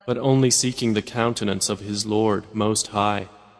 متن، ترجمه و قرائت قرآن کریم